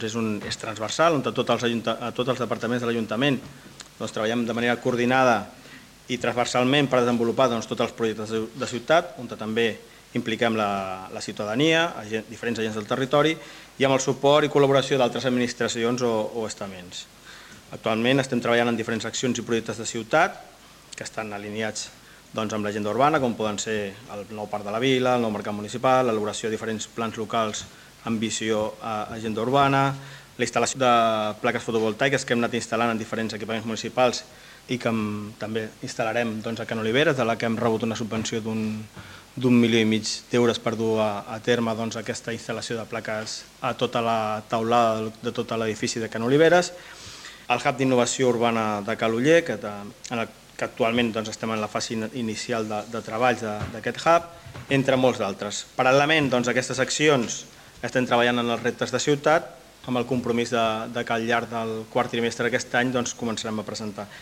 PLe Municipal. Juliol de 2025
Albert Fernández, regidor d'Agenda Urbana i de Planificació Urbanística